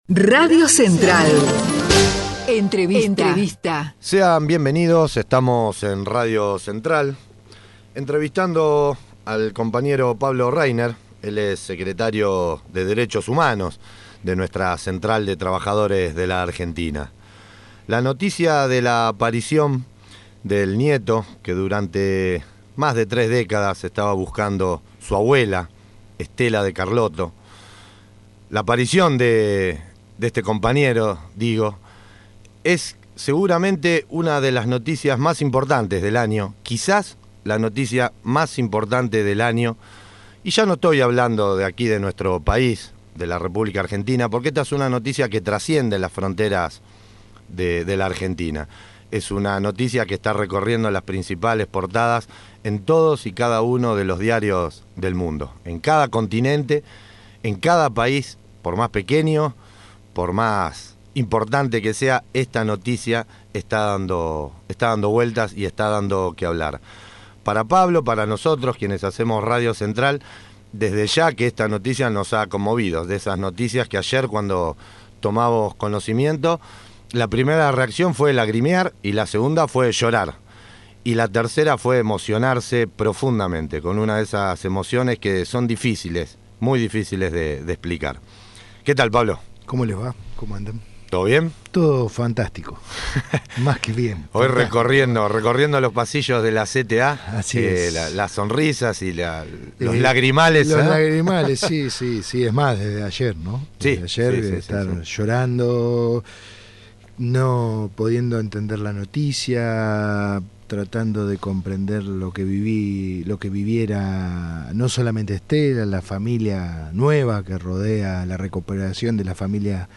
entrevista RADIO CENTRAL